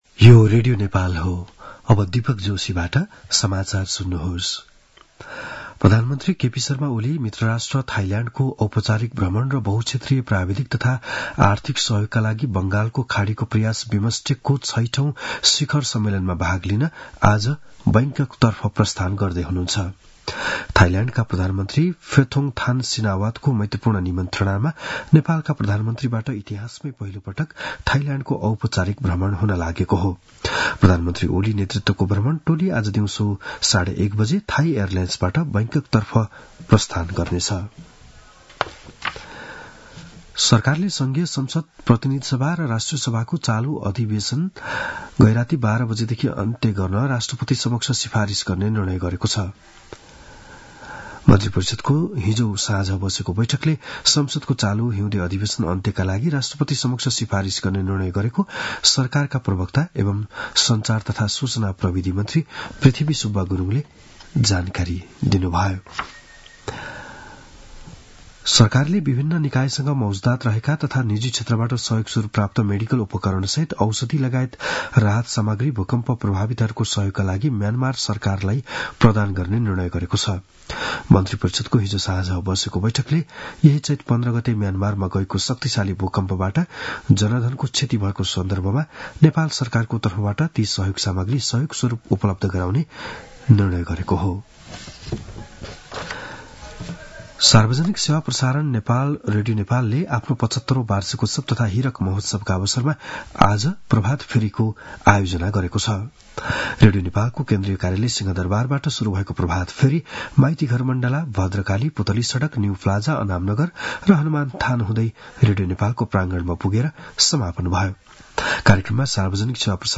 बिहान ११ बजेको नेपाली समाचार : १९ चैत , २०८१
11-am-news-.mp3